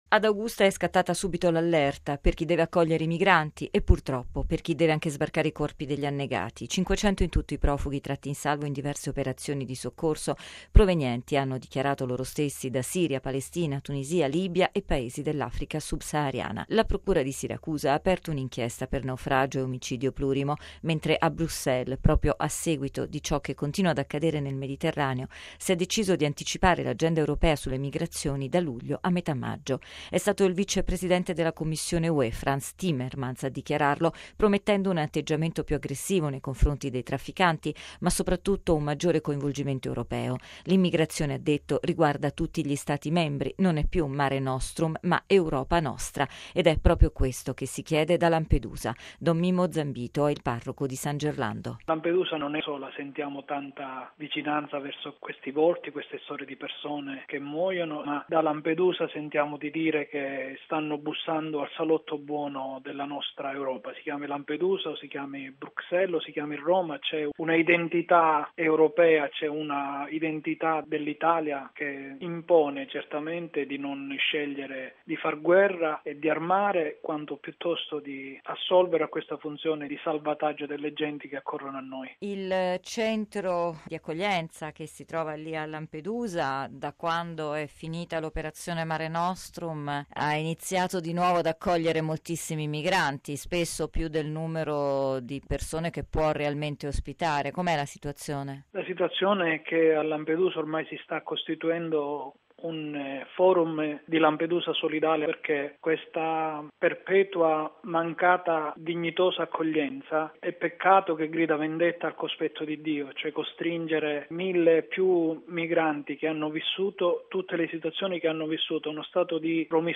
Ancora morti nel Canale di Sicilia: dieci i cadaveri ripescati dalla Guardia costiera dopo il rovesciamento di un gommone. Sono centinaia i migranti tratti in salvo nelle ultime ore. Il servizio